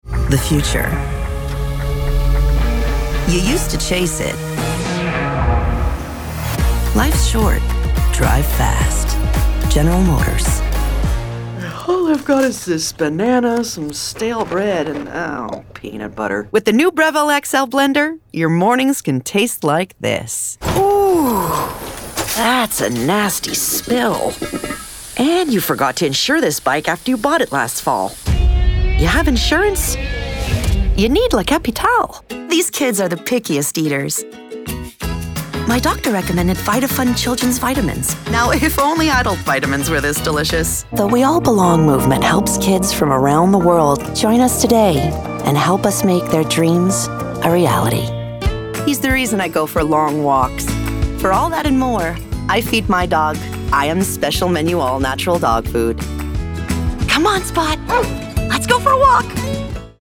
Publicités - ANG